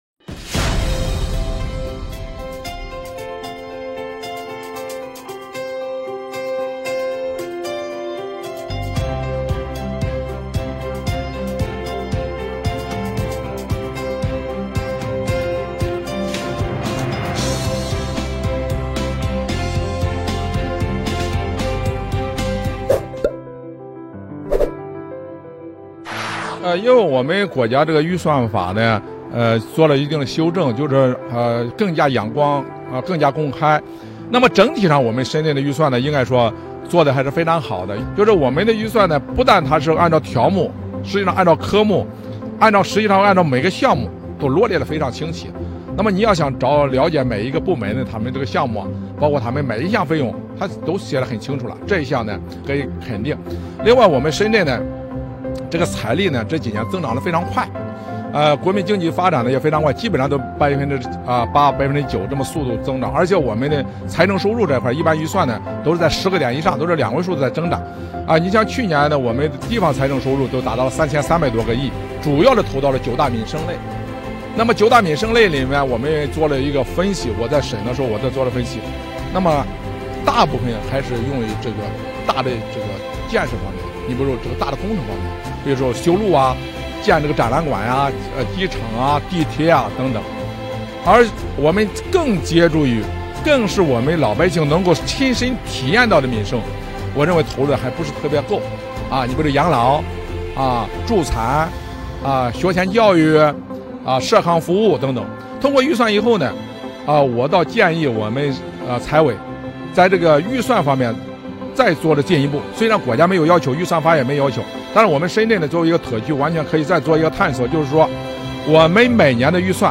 2018年人代会现场直击：人大代表和市民“话”预算
那么，接下来就让我们听听代表和深圳市民最关心哪些领域的投入和发展，都有哪些具体的建议呢？